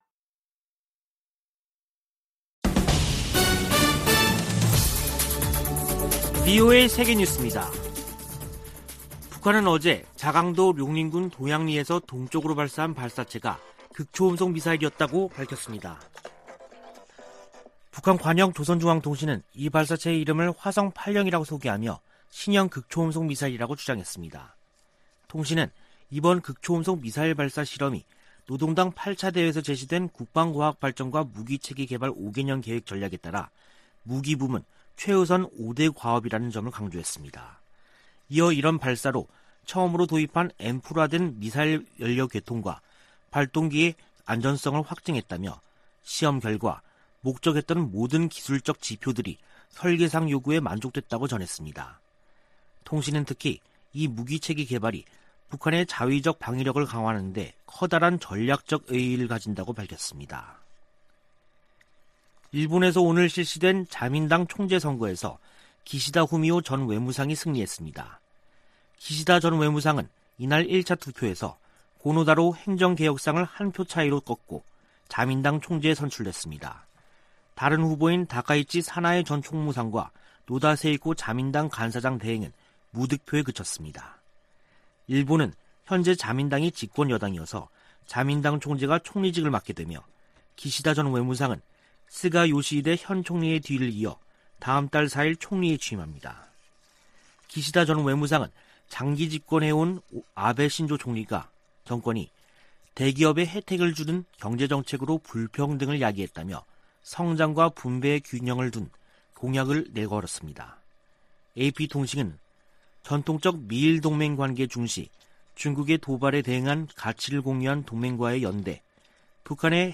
VOA 한국어 간판 뉴스 프로그램 '뉴스 투데이', 2021년 9월 29일 2부 방송입니다. 미국 국무부 고위 관리가 북한의 최근 탄도미사일 발사들을 우려하며 규탄한다고 밝혔습니다. 북한이 유화적 담화를 내놓은 뒤 미사일을 발사한 것은 대미 협상에서 우위를 확보하기 위한 것으로 미 전직 관리들은 분석했습니다. 국제사회는 북한의 탄도미사일 발사를 규탄하고, 불법 행위를 멈출 것을 촉구했습니다.